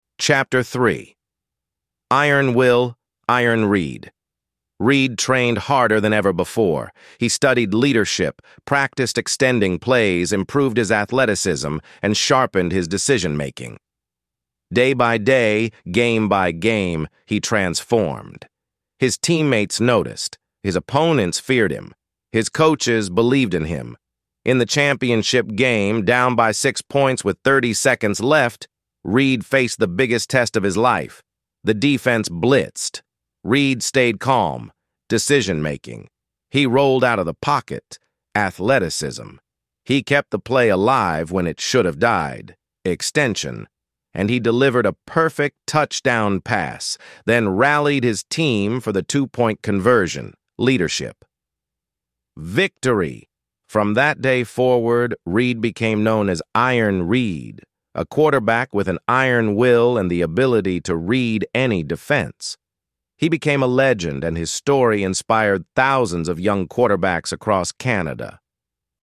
ElevenLabs_2026-01-27T15_06_59_Adam – Dominant, Firm_pre_sp89_s41_sb48_se0_b_m2